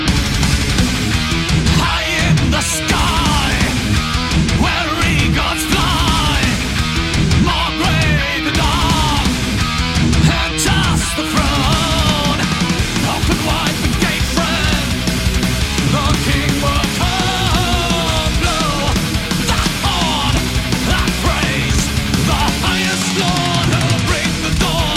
Музыка » Rock » Rock